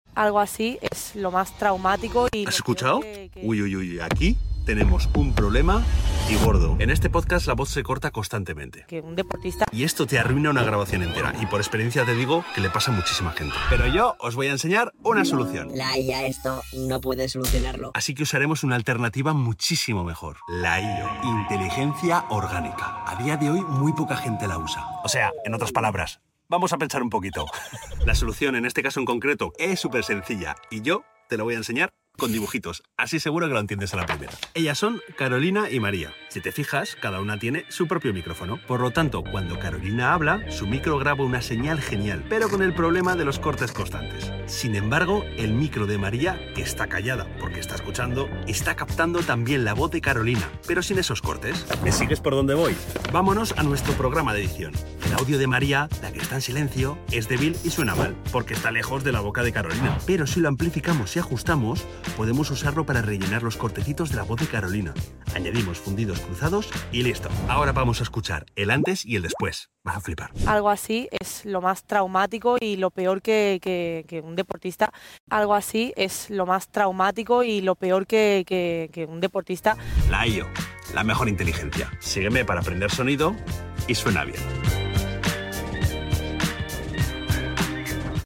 Por que la IA no diferencia entre corte de sonido y silencio🤖 Si disponemos de dos o más grabaciones independientes esta solución puede minimizar tu audio dañado, pero eso si, ármate de paciencia! En este podcast he tenido que restaurar alrededor de 600 cortes de sonido!